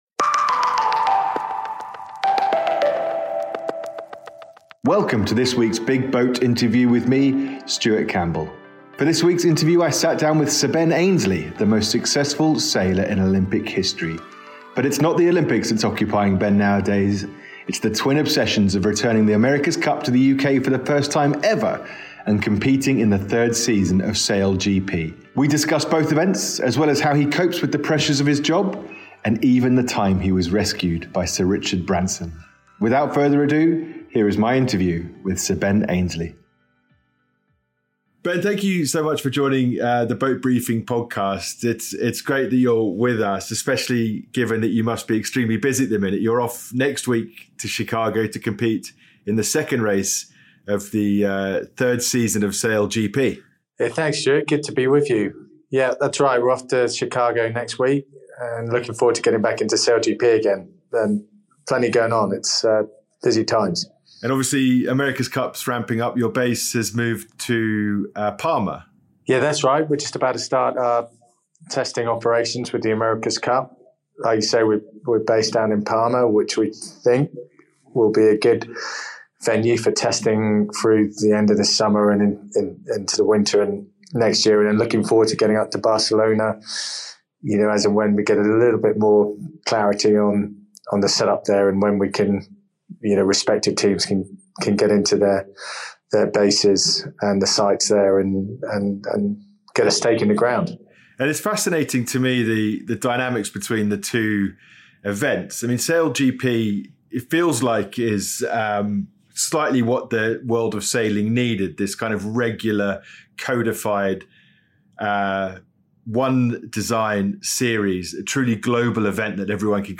In this week's Big BOAT Interview, we sit down with the most successful Olympic sailor of all time, Sir Ben Ainslie.